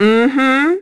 Juno-Vox-Deny_kr.wav